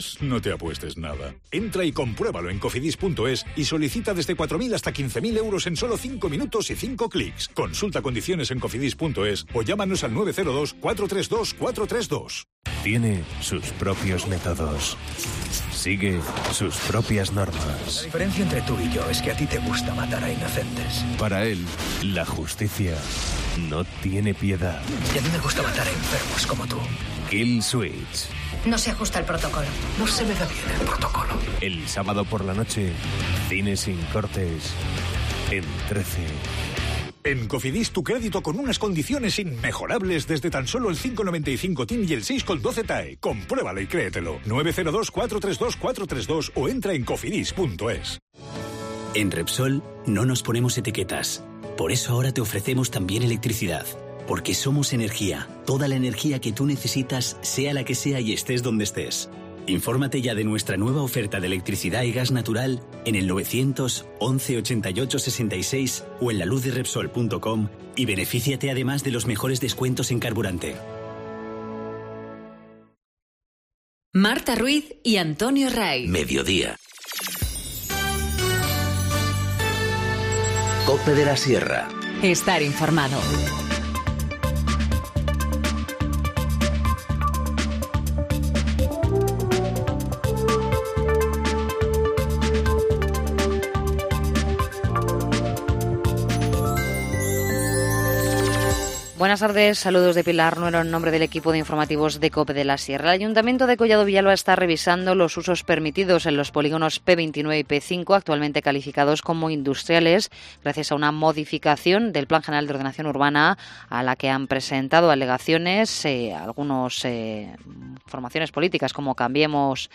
Informativo Mediodía 4 dic- 14:20h